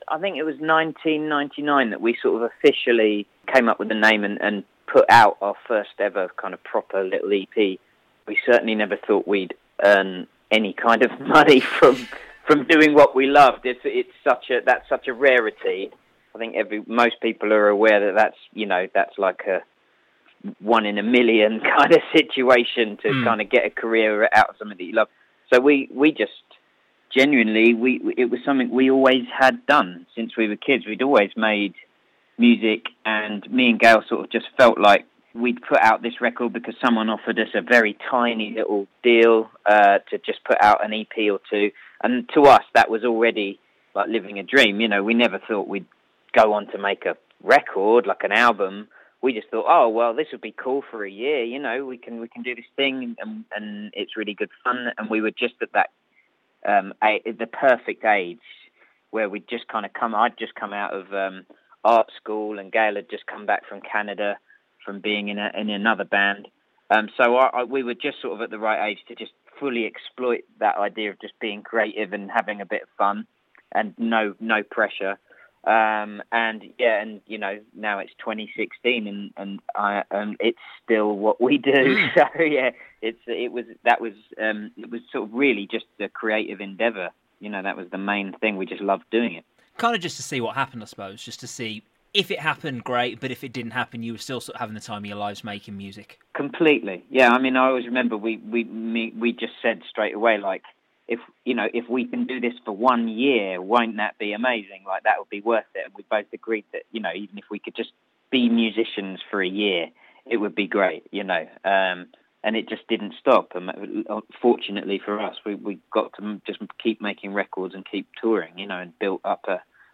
Olly Knights from the band tells me how Turin Breaks are back and better than ever with their new single, new album and a headline tour as well!